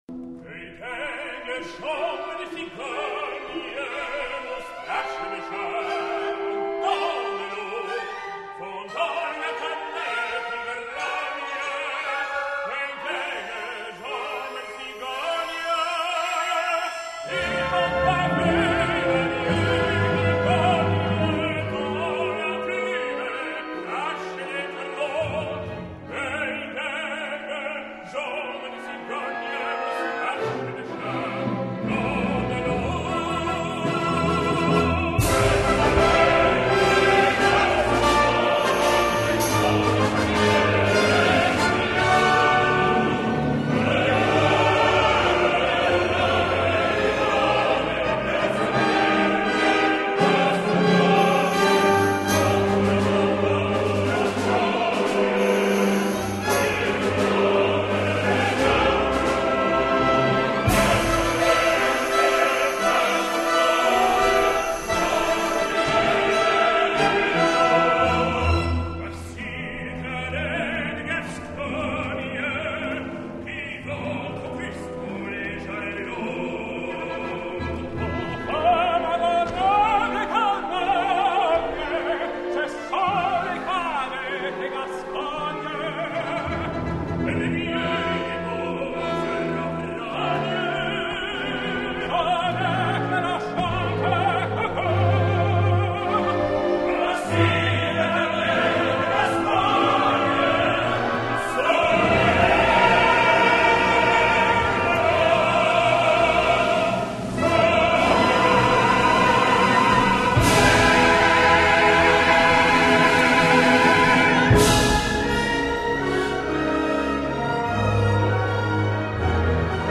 französisch / french
Carbon, Cyrano & choeur